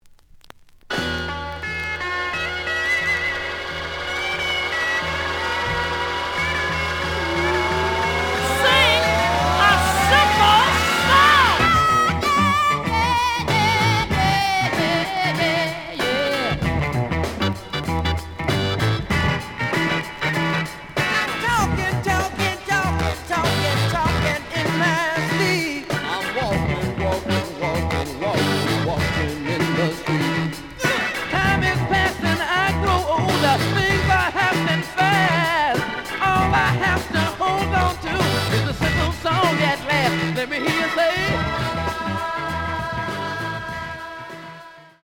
The audio sample is recorded from the actual item.
●Genre: Funk, 60's Funk